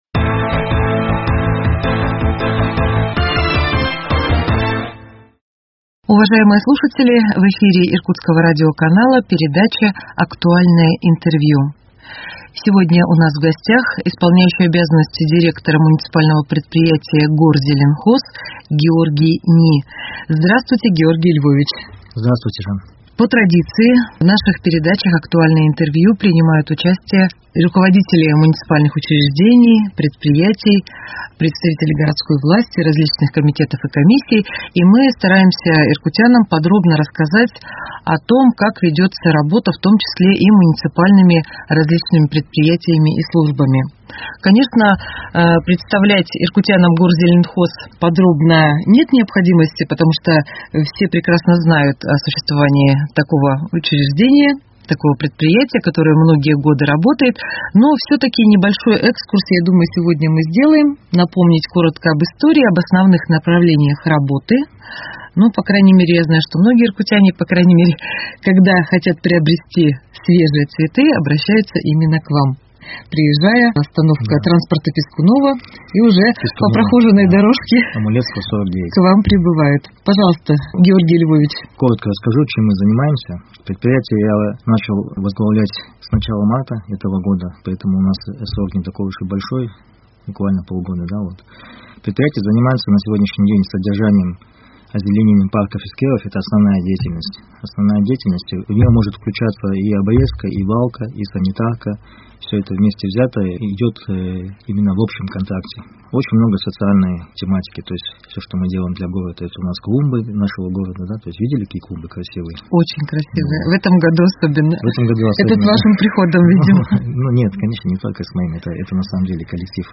Актуальное интервью: МУПЭП «Горзеленхоз» 27.09.2021